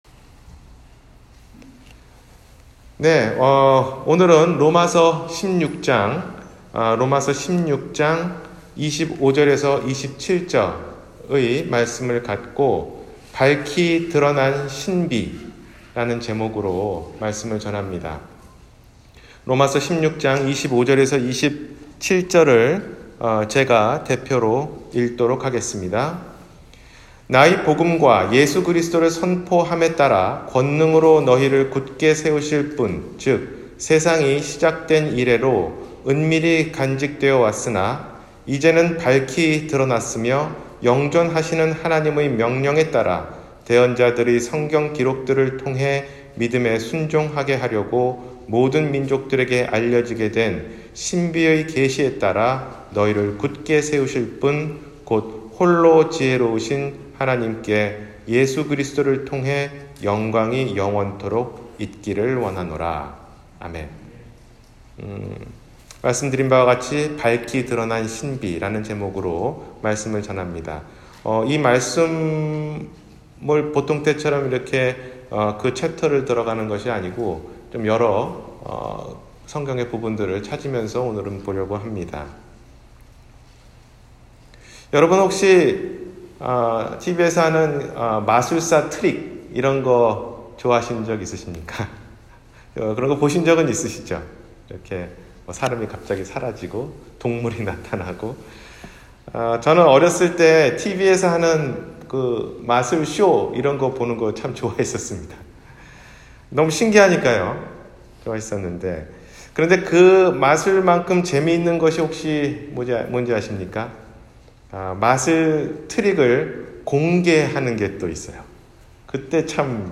밝히 드러난 신비 – 주일설교